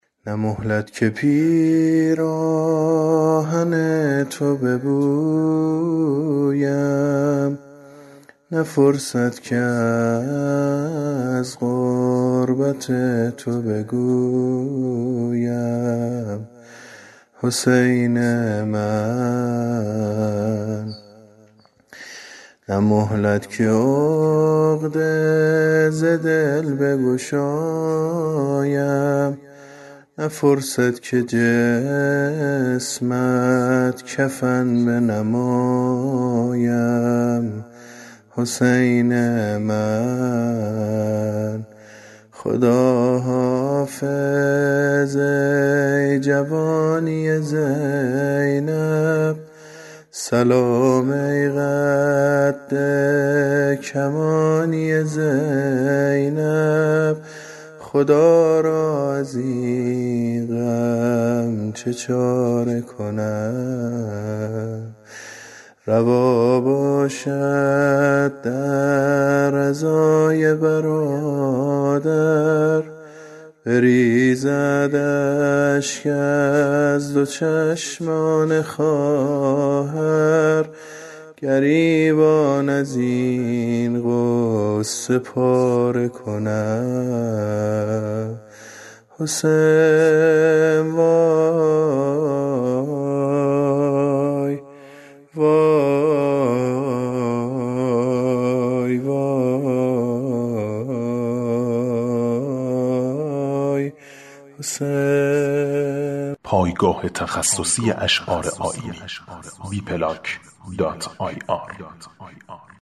زمزمه